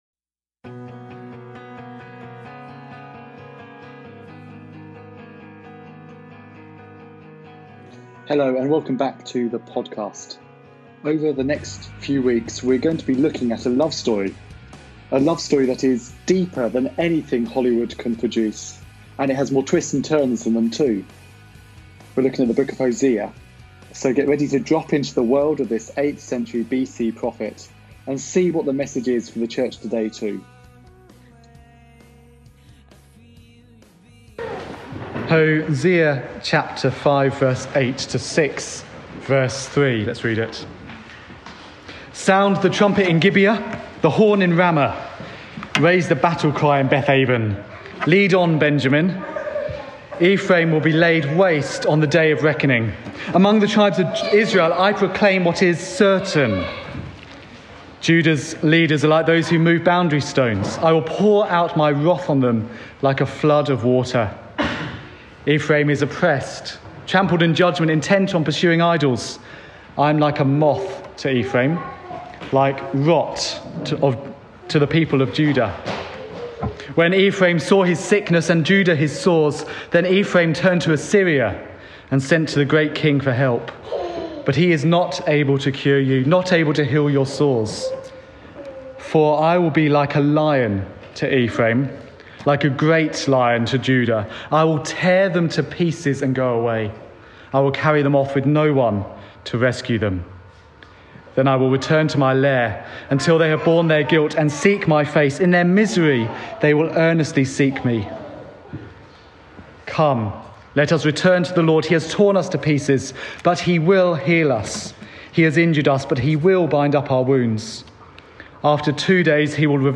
This was preached at Christ Church Hemel on 16th February 2020.